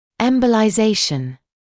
[ˌembəlaɪ’zeɪʃn][ˌэмбэлай’зэйшн]эмболизация